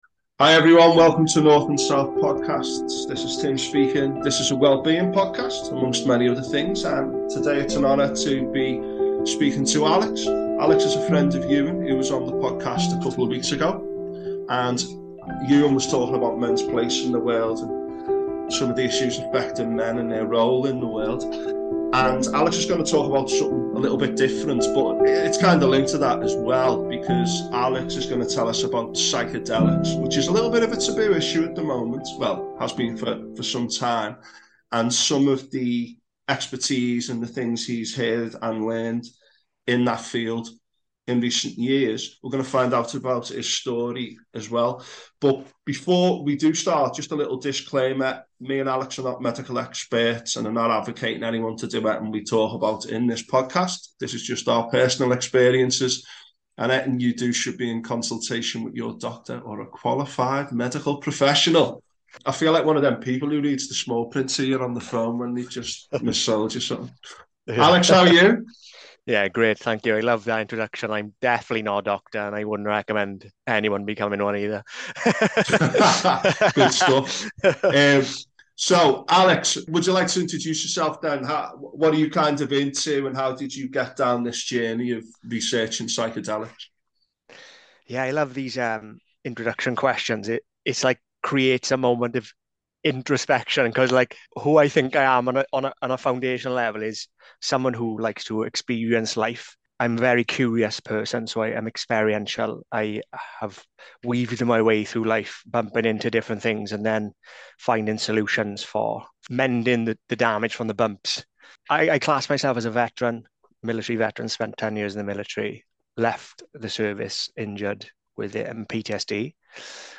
We also go on a journey into mental trauma, addiction, recovery, and transcendence. This is a beautiful conversation.